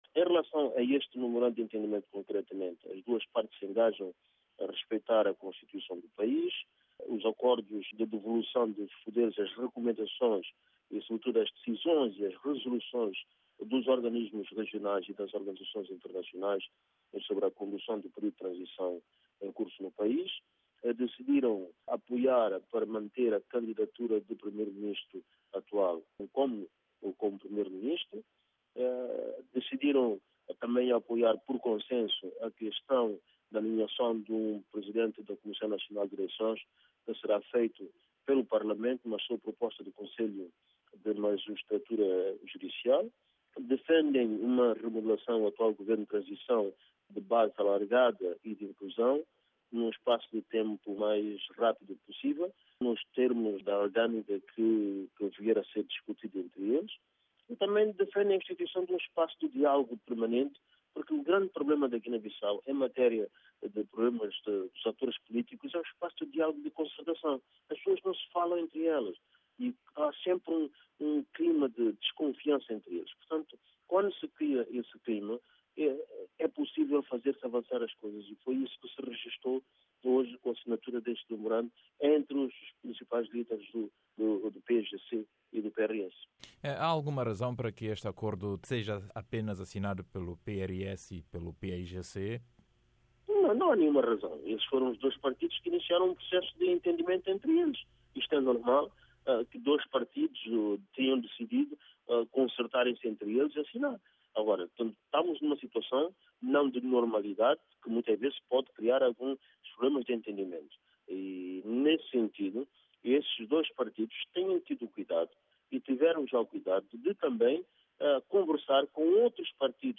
Entrevista com Emb. Ovídio Pequeno - 03:27